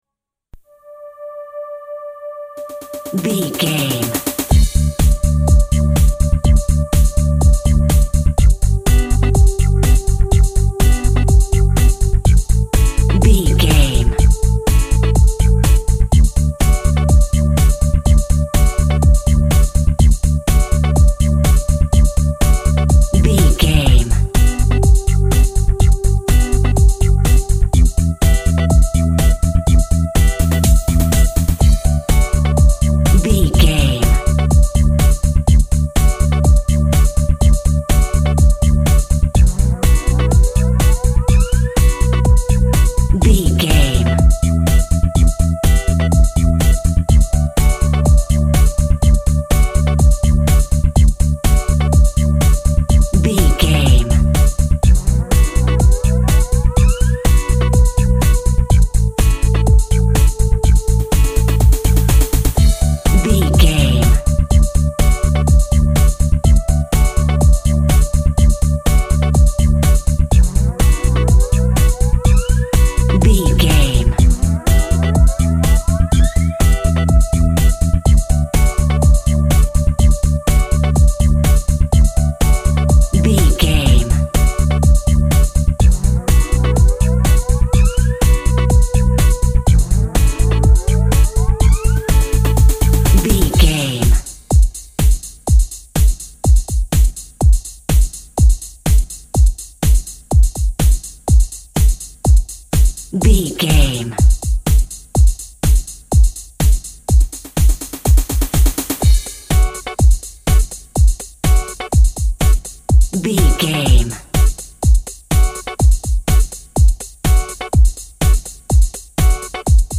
Electronic Discovery.
Ionian/Major
D
groovy
futuristic
hypnotic
uplifting
drum machine
electric guitar
techno
trance
synth lead
synth bass
Synth Pads